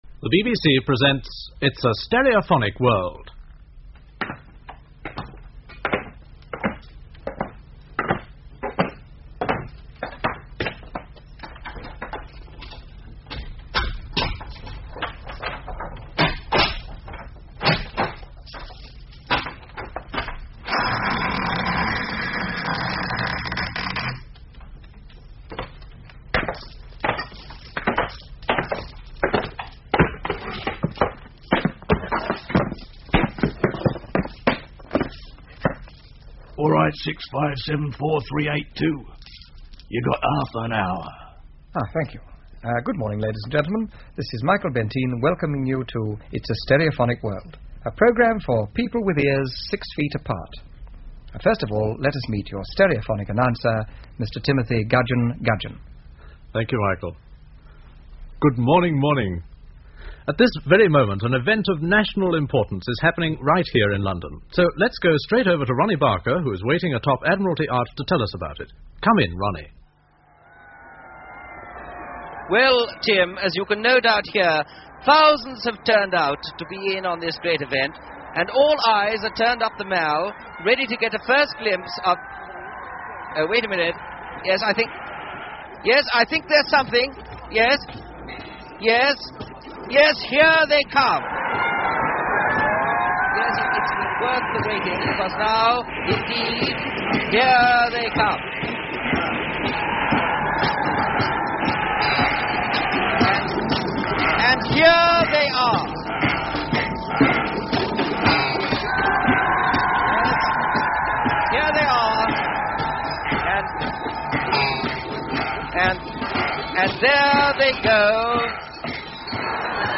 Archived copy of the experimental stereophonic broadcast It's A Stereophonic World
At the time this programme was made, neither radio nor TV were capable of sterephonic broadcasting, so Radio 3 (known at the time as Network Three) was used for the left channel and BBC TV was used for the right channel, sound only.
It is probably best to listen to this using good quality headphones.